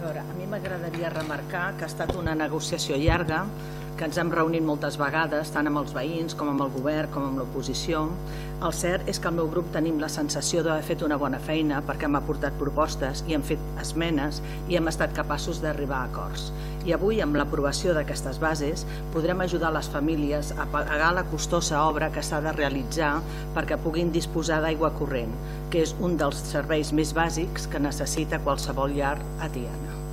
La portaveu de Junts per Catalunya Tiana, Montse Torres, va afegir que havia sigut una negociació llarga: